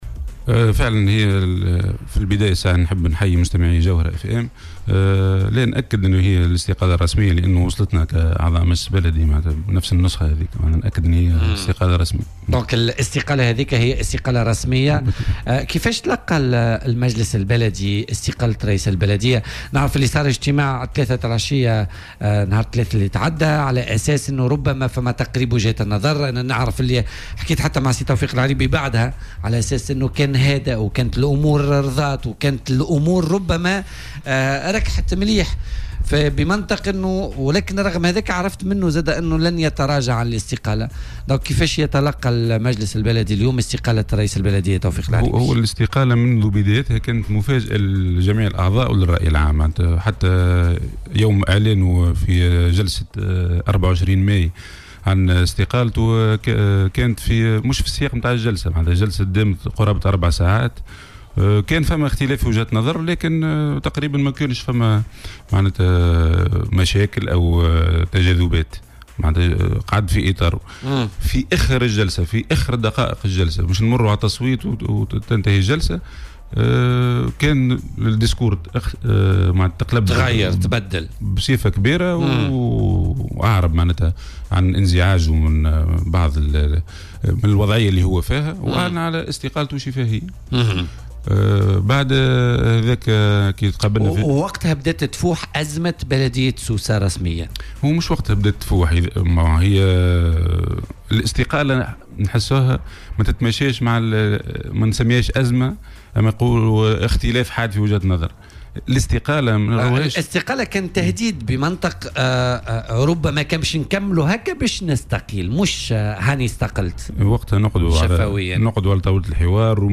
وأضاف ضيف "بوليتيكا" على "الجوهرة اف ام"، أن الاستقالة كانت مفاجئة لأعضاء المجلس البلدي بسوسة وللرأي العام، معتبرا أن الاختلاف في وجهة النظر خلال جلسة لا يرتق إلى درجة الاستقالة، وفق ترجيحه.